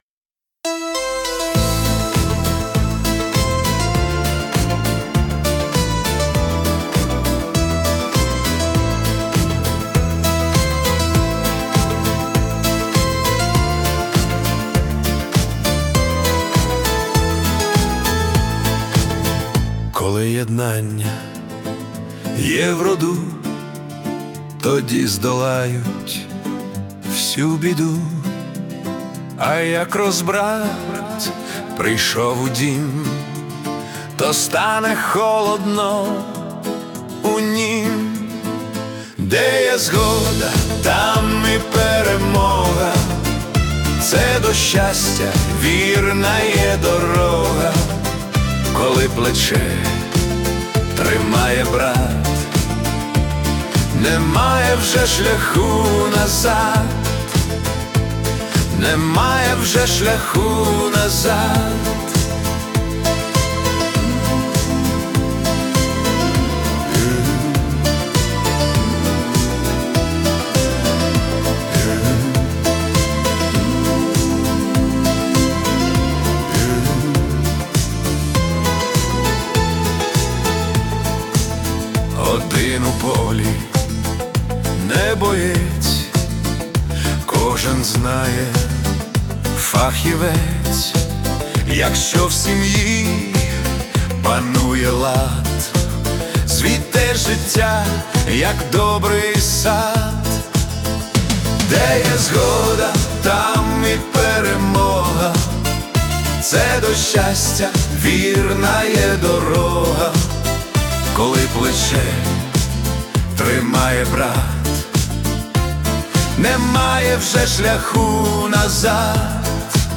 це потужна патріотична балада